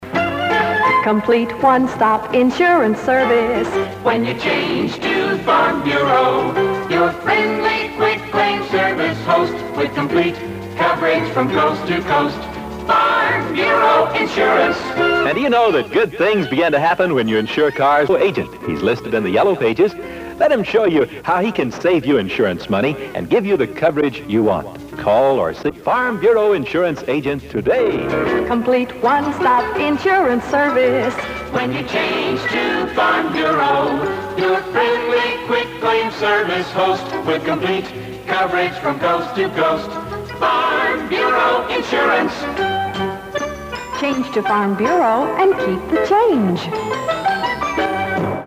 Here's a Farm Bureau radio spot from 1968,
Unfortunately, there's an edit in the commercial, where that part was taped over.  At least we get that great jingle in its entirety (which has the unmistakable sound of a Boutwell Studios production).